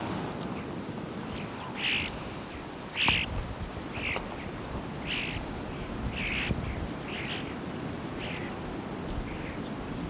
European Starling Sturnus vulgaris
Flight call description A soft, low, growling "whrrsh" is the primary flight call.
"Whrrsh" calls from flock in flight. Yellow-rumped Warbler calling in the background.